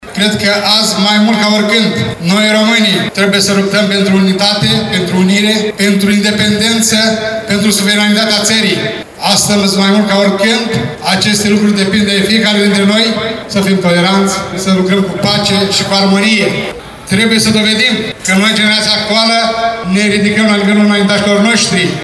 În centrul municipiului Suceava s-a desfășurat ceremonia militară cu prilejul Zilei Naționale, la care au luat parte cadre și tehnică de la Batalionului Artilerie Alexandru cel Bun Botoșani, Colegiul Militar Ștefan cel Mare Câmpulung Moldovenesc și Școala de Subofițeri Jandarmi Fălticeni.
La rândul său, primarul ION LUNGU a rememorat succint momentele importante din istoria românilor, precizând că dezvoltarea țării trebuie să se facă în armonie.